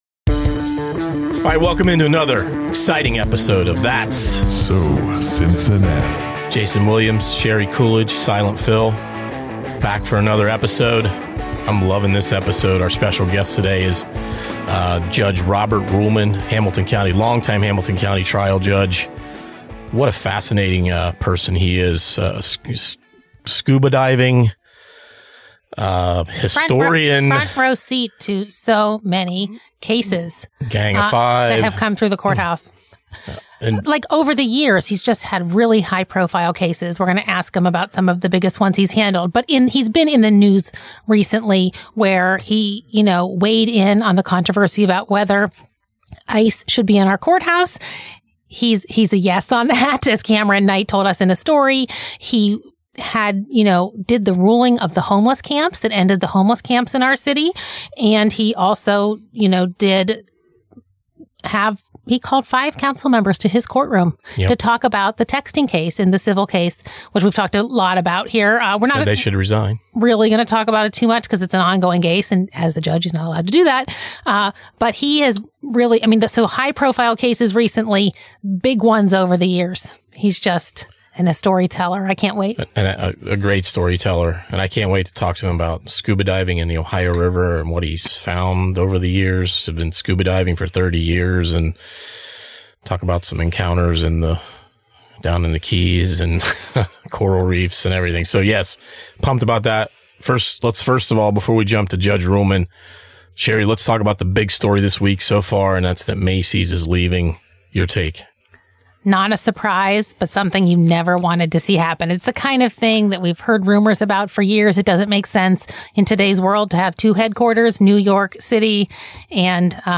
He showed that in an in-depth interview this week on The Enquirer's That's So Cincinnati podcast.